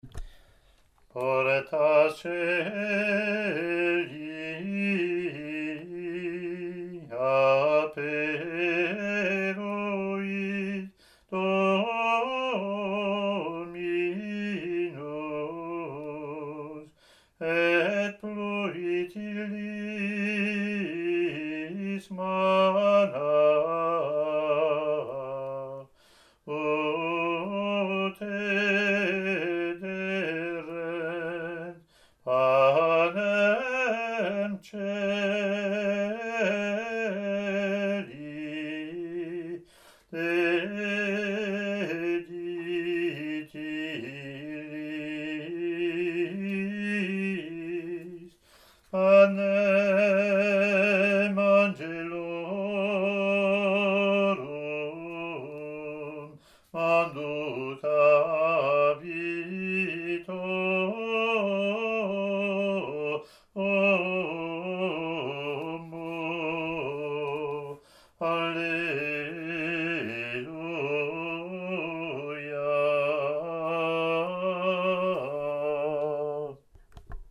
Latin antiphon)